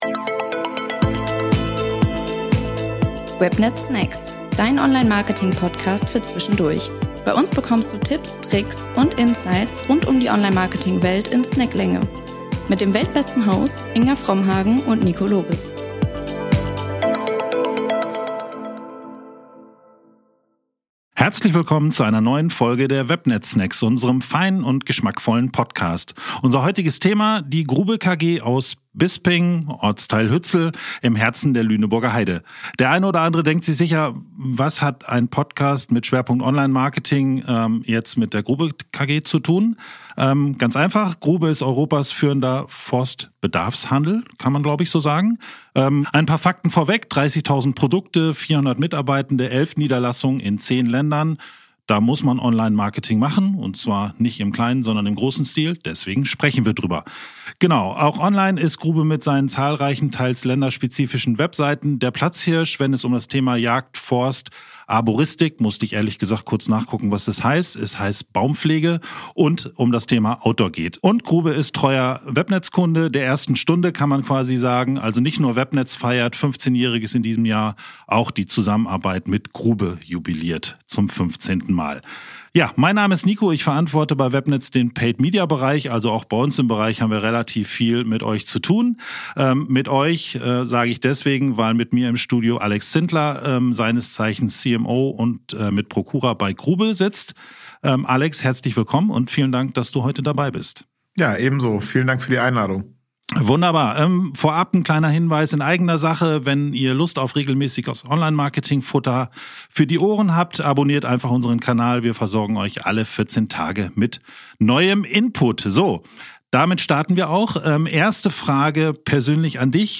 In unserem Gespräch erkunden wir den Weg von Grubes Gründung 1945 bis heute und wie sich das Unternehmen vom regionalen Forstgerätehändler zum internationalen Omnichannel-Anbieter für Jagd, Forst, Arboristik und Outdoor entwickelt hat.